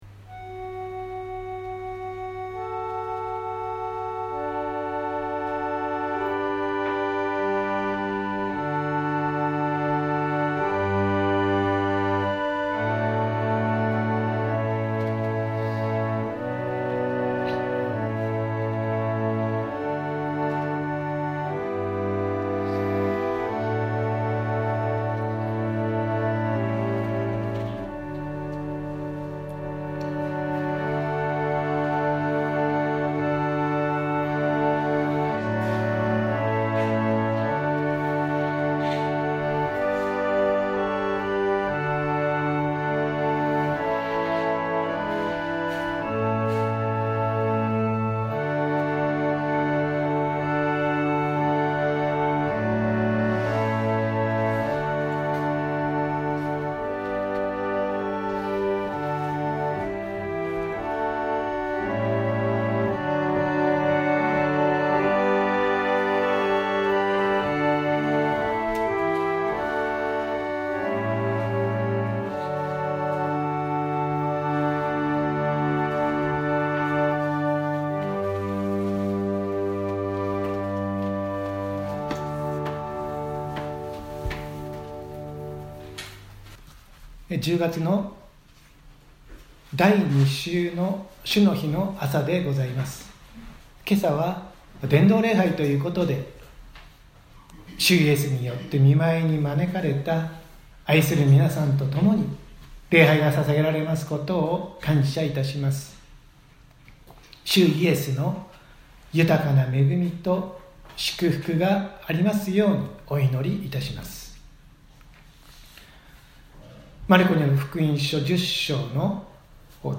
説教アーカイブ。
音声ファイル 礼拝説教を録音した音声ファイルを公開しています。